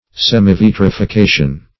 Search Result for " semivitrification" : The Collaborative International Dictionary of English v.0.48: Semivitrification \Sem`i*vit"ri*fi*ca"tion\, n. 1.